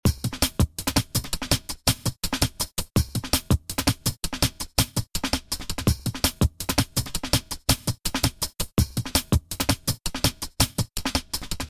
For this tutorial the drum n bass loop FullCycle is used, which can be found in the Reason Factory Sound Bank -> Dr Rex Drum Loops -> Drum N Bass. The tempo of the loop is 165 bpm.
Time to add some more dynamic filtering.
Sync the modulators and change their rate to 1/4, as shown in the following figure: